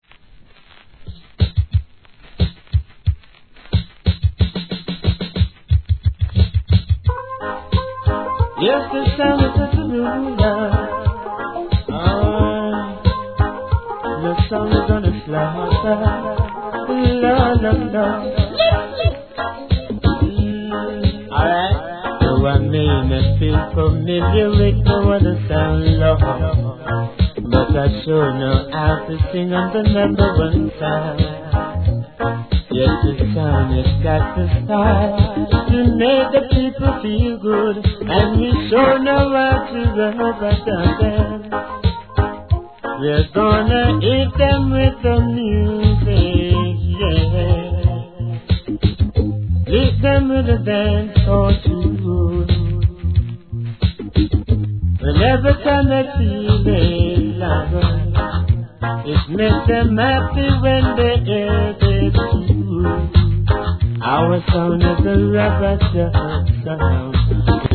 REGGAE
SWEET VOICEでのサウンドKILLER!!